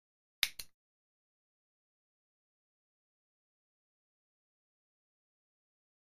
Misc. Sports Elements; Clicking Of Start And Stop Button.